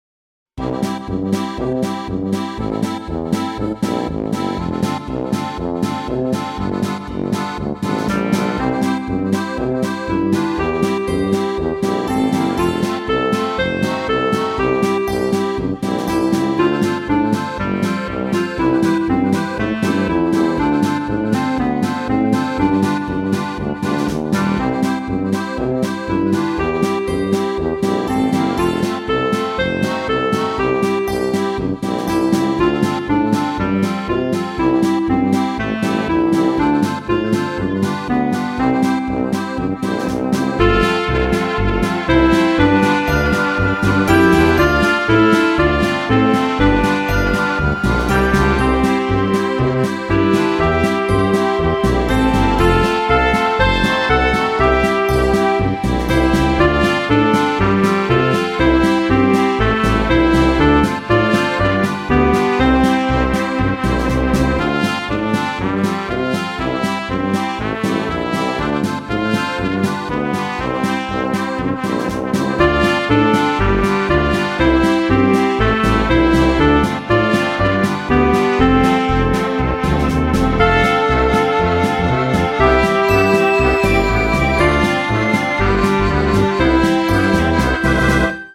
Oberkroiler